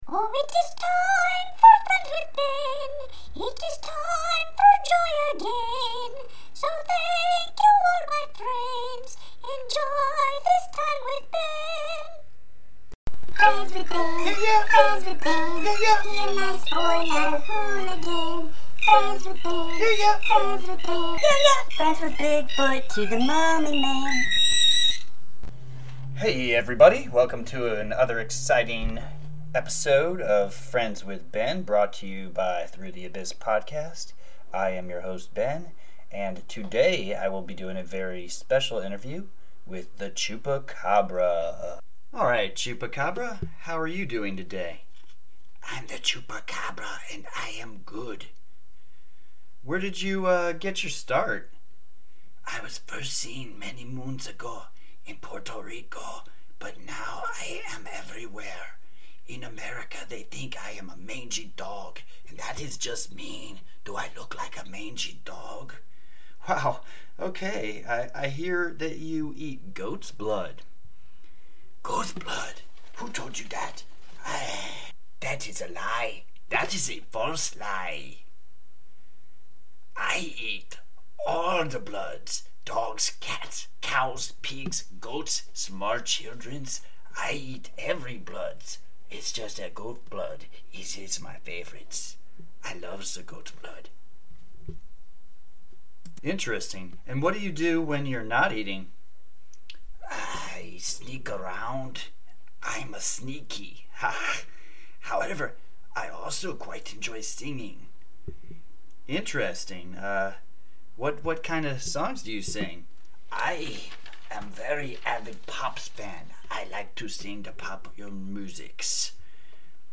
interviews the urban legend Chupacabra!